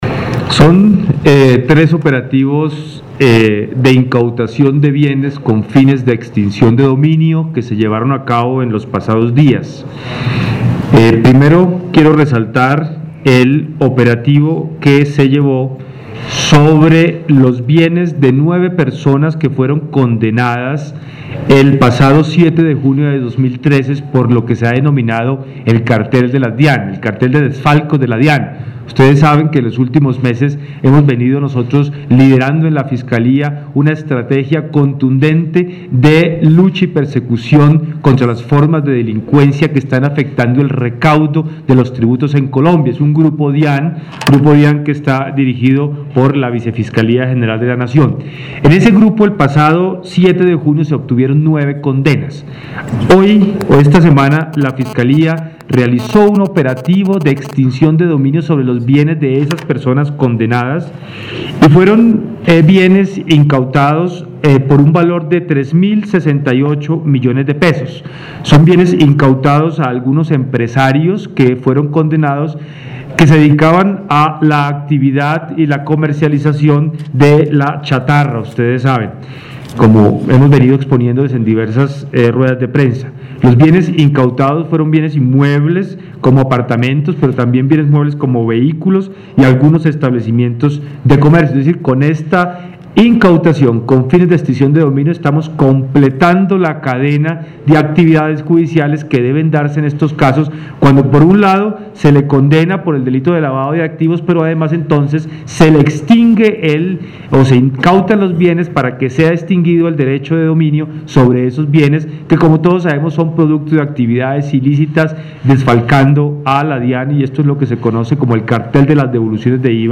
En rueda de prensa, el Vicefiscal Jorge Fernando Perdomo, aclaró que “las acciones de extinción de dominio no sólo se adelantan contra personas vinculadas al narcotráfico o al lavado de activos, sino que la extinción de dominio se puede iniciar contra cualquier persona que no pueda justificar sus bienes o el monto de sus bienes y la legalidad de los mismos”.
Declaraciones Vicefiscal General de la Nación, Jorge Fernando Perdomo Torres;  Director Dijin, general Jorge Hernando Nieto Rojas y Subdirector Policía Antinarcóticos, coronel Esteban Arias
Lugar: Nivel Central Fiscalía General de la Nación.  Bogotá, D.C.